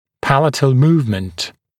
[‘pælətl ‘muːvmənt][‘пэлэтл ‘му:вмэнт]небное перемещение, оральное перемещение (о зубах верхней челюсти)